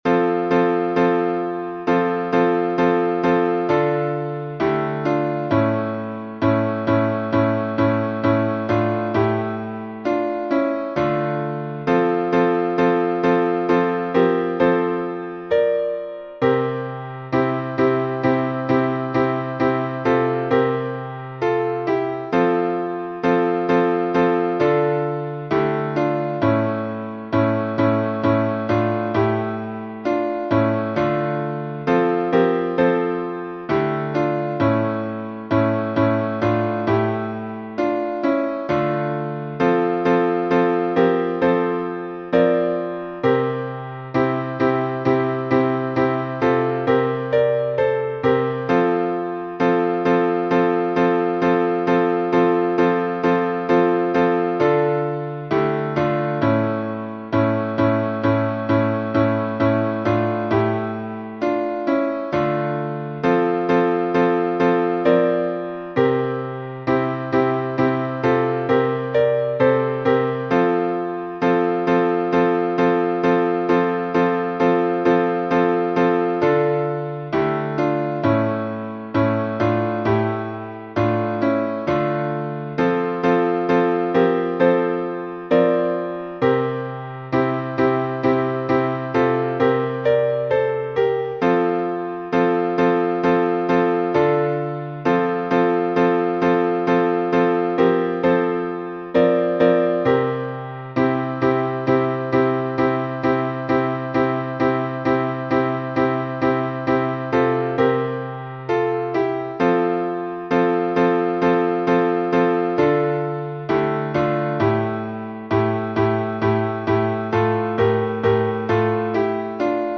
sarov_chant.mp3